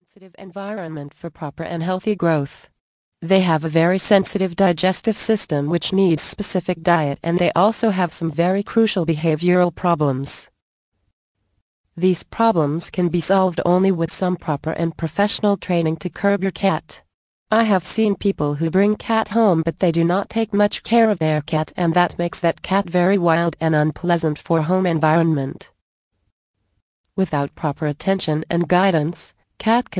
Curb Your Cat. Training Made Easy audio book + GIFT